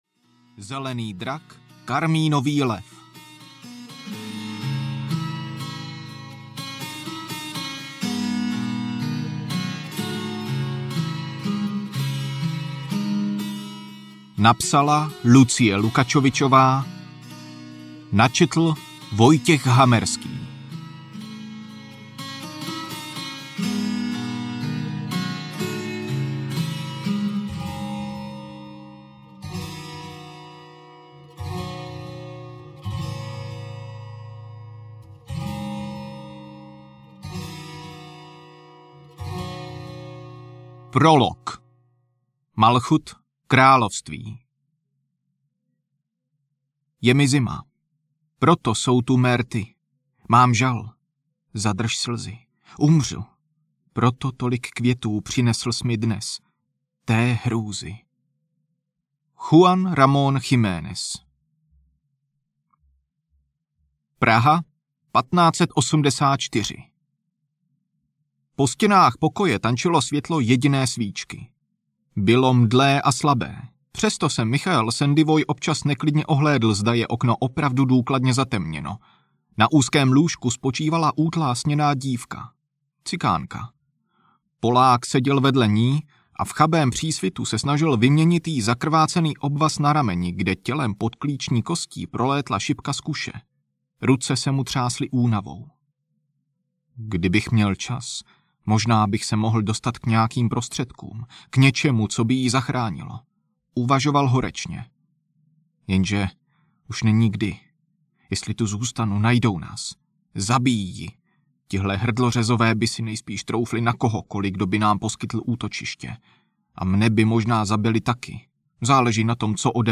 Zelený drak, karmínový lev audiokniha
Ukázka z knihy
zeleny-drak-karminovy-lev-audiokniha